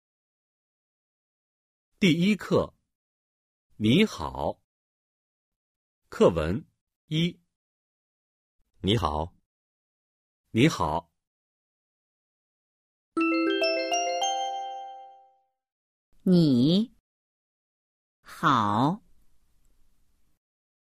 #1. Hội thoại 1